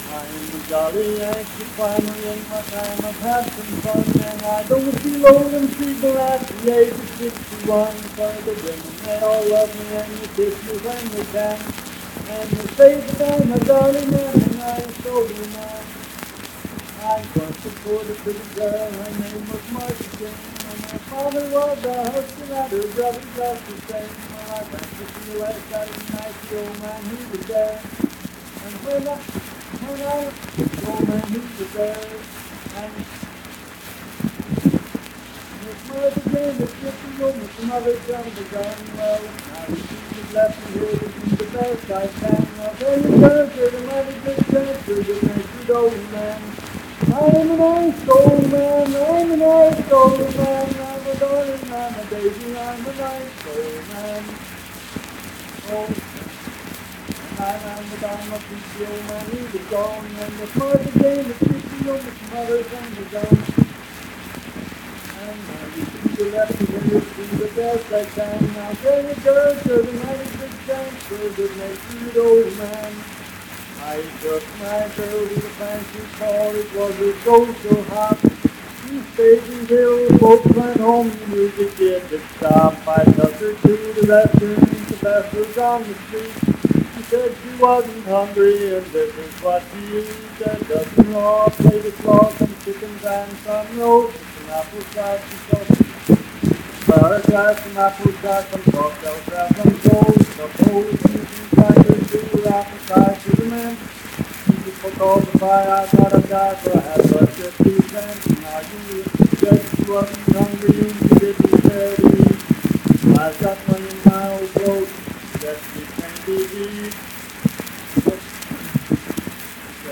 Unaccompanied vocal music performance
Verse-refrain 1(5).
Voice (sung)